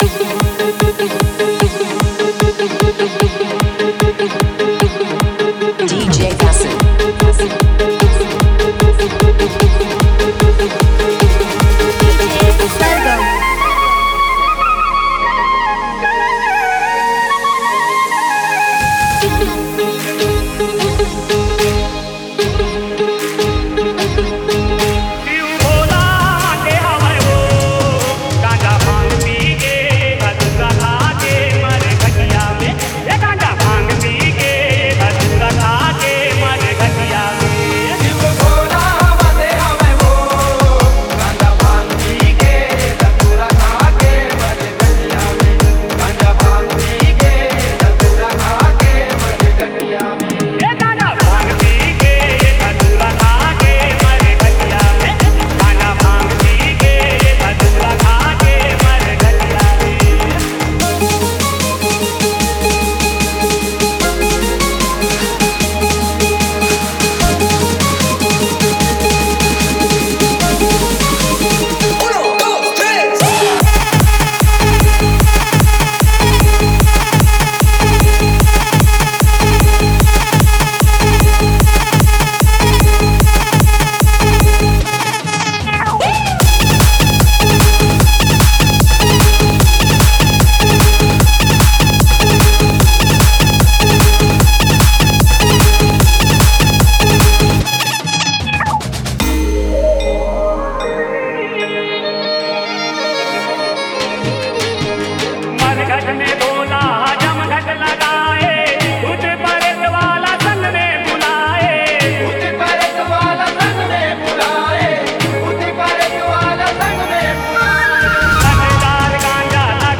Category: Bolbum Remix Songs